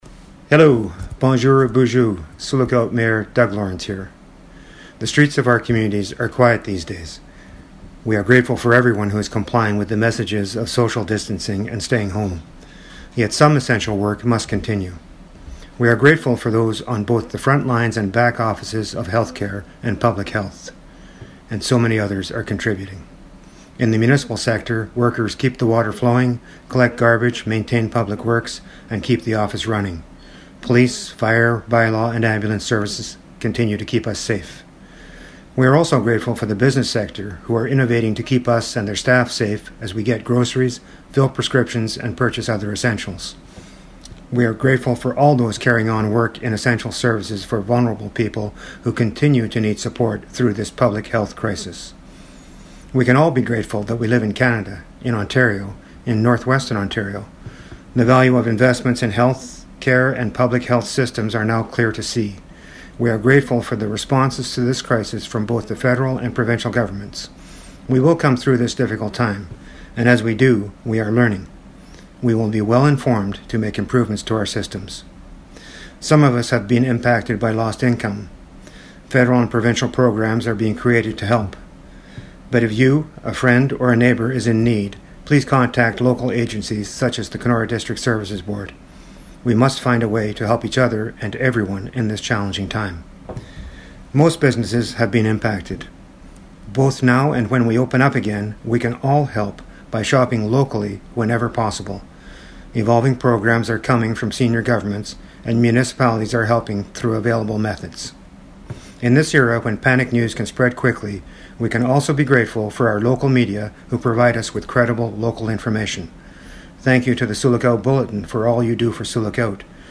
Mayor Doug Lawrance issued an update on the health crisis on the CKDR Morning Show today (Wednesday) and stressed all essential services continue.